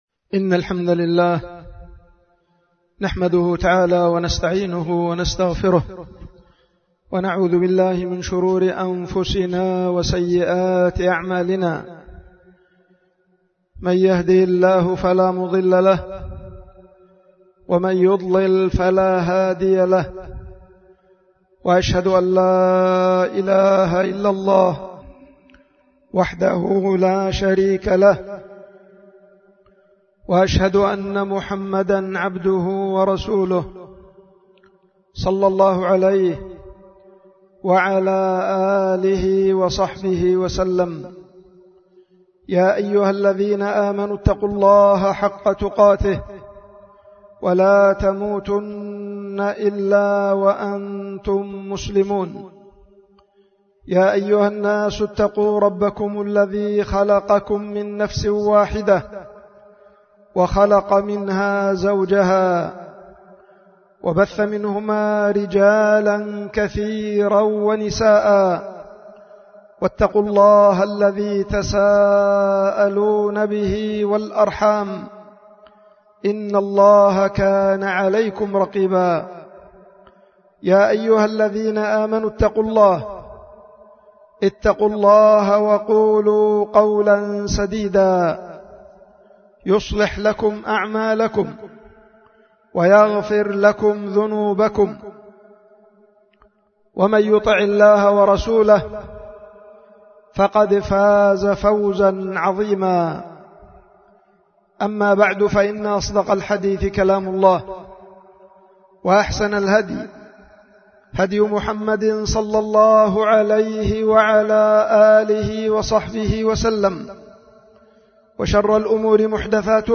خطبة الجمعة
القيت في دار الحديث في مدينة دار السلام العلمية بيختل المخا